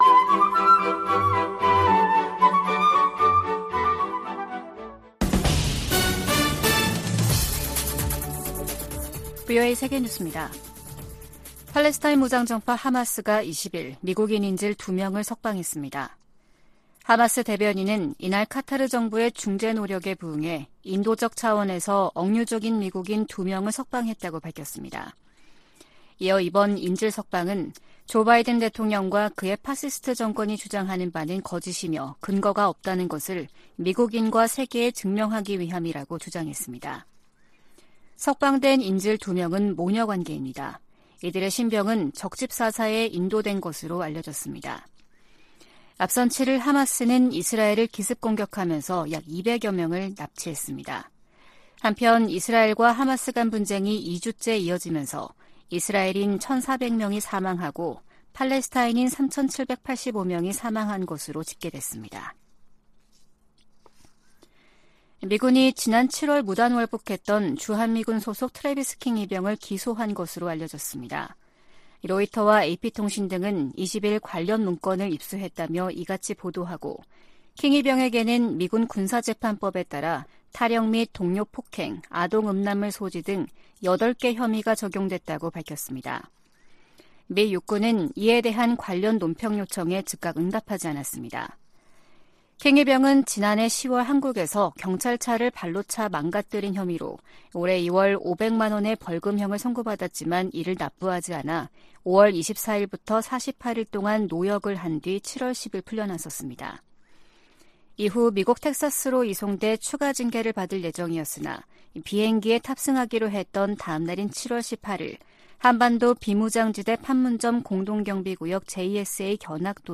VOA 한국어 아침 뉴스 프로그램 '워싱턴 뉴스 광장' 2023년 10월 21일 방송입니다. 김정은 북한 국무위원장이 19일 세르게이 라브로프 러시아 외무장관을 접견하고 안정적이며 미래지향적 양국 관계의 백년대계를 구축하자고 말했습니다. 미 국무부가 북-러 무기 거래를 좌시하지 않을 것이라는 입장을 밝혔습니다.